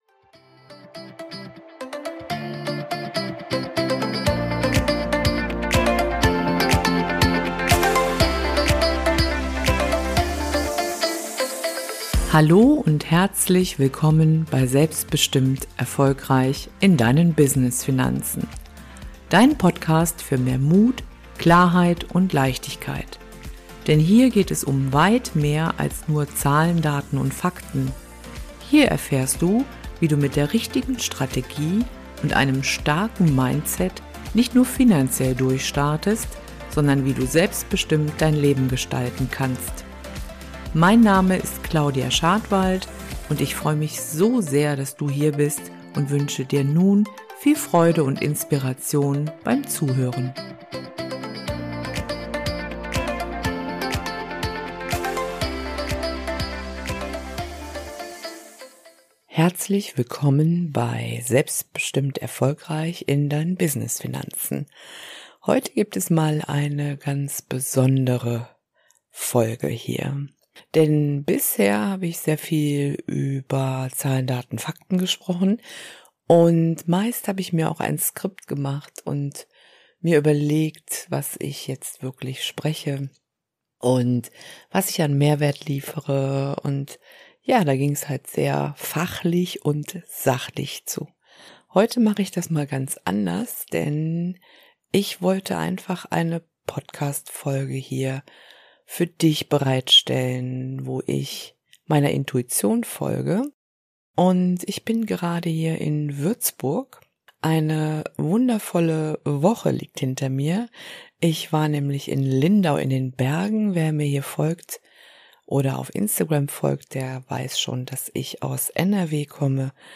In dieser besonderen Folge von Selbstbestimmt erfolgreich in deinen Business Finanzen spreche ich ohne Skript über die Wichtigkeit von Reflektion und einem starken Mindset.